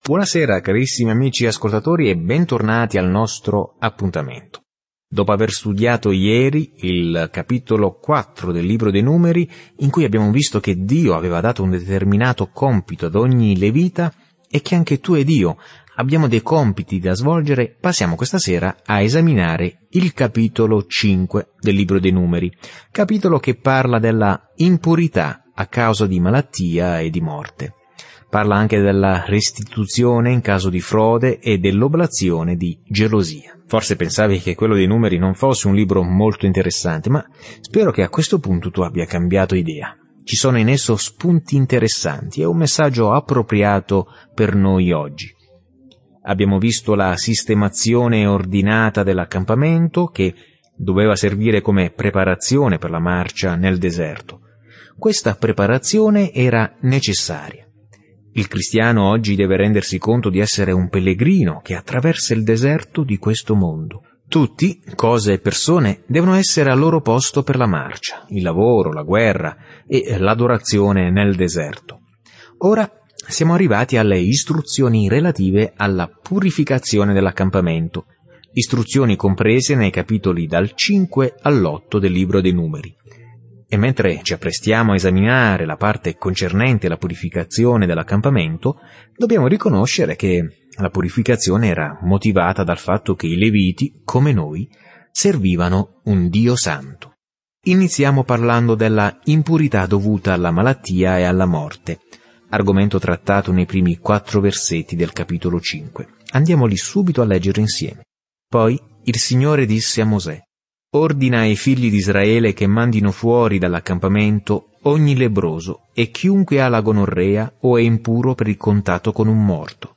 Scrittura Numeri 5:1-31 Numeri 6:1-7 Giorno 3 Inizia questo Piano Giorno 5 Riguardo questo Piano Nel libro dei Numeri, camminiamo, vaghiamo e adoriamo con Israele durante i 40 anni nel deserto. Viaggia ogni giorno attraverso Numeri mentre ascolti lo studio audio e leggi versetti selezionati della parola di Dio.